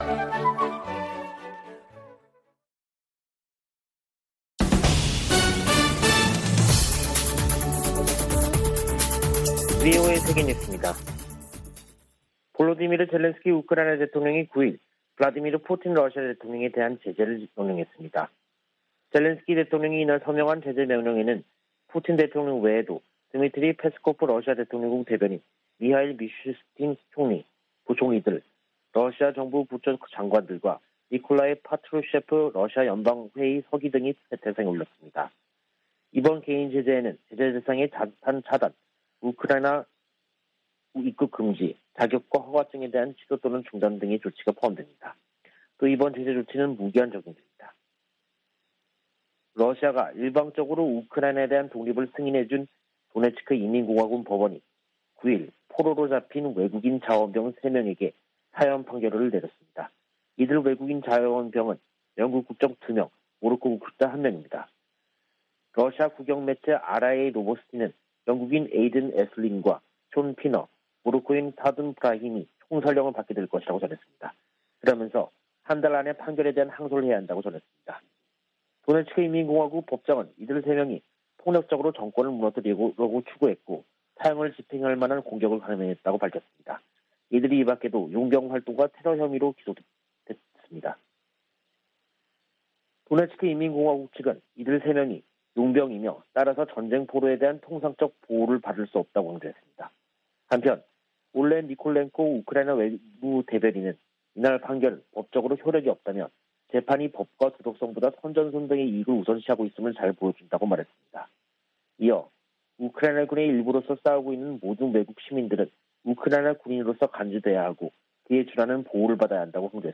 VOA 한국어 간판 뉴스 프로그램 '뉴스 투데이', 2022년 6월 10일 2부 방송입니다. 윤석열 한국 대통령이 한국 정상으로는 처음 나토 정상회의에 참석합니다. 북한이 핵실험을 강행하면 억지력 강화, 정보유입 확대 등 체감할수 있는 대응을 해야 한다고 전직 미국 관리들이 촉구했습니다. 유엔 주재 중국대사가 북한의 추가 핵실험을 원하지 않는다는 입장을 밝히면서도, 실험을 강행할 경우 중국의 반응을 추측해서도 안된다고 말했습니다.